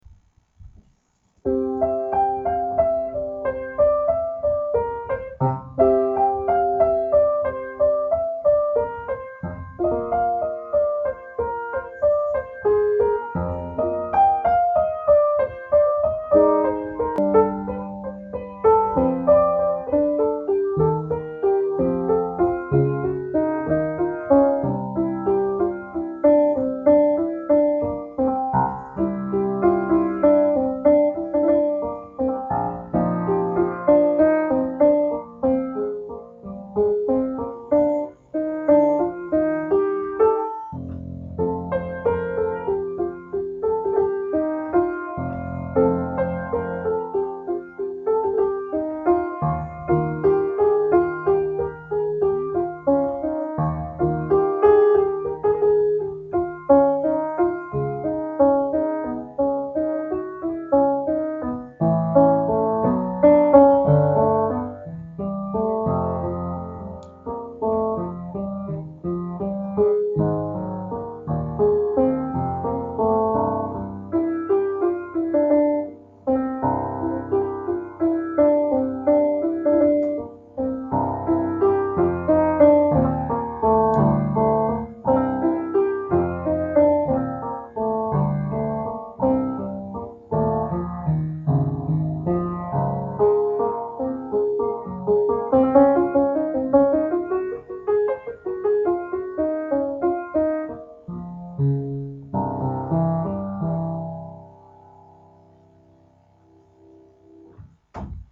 Keyboard / 2007